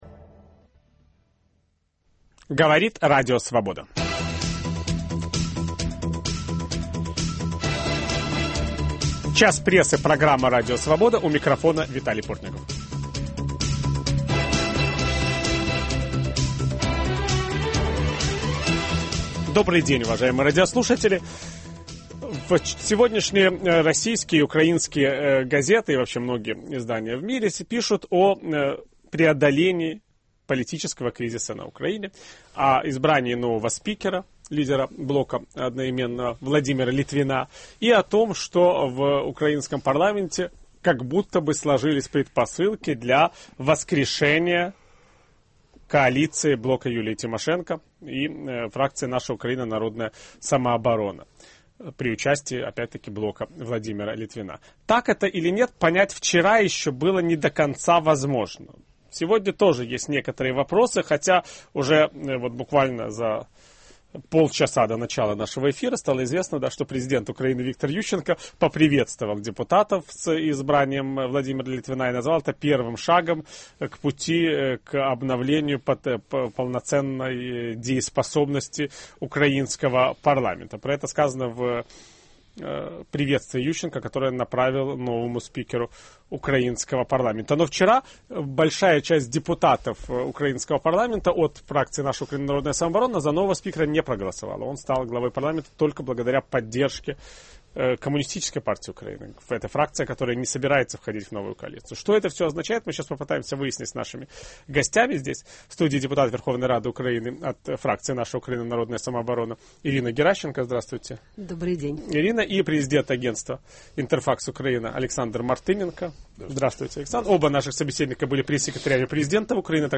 В программу участвуют депутат Верховной Рады Украины Ирина Геращенко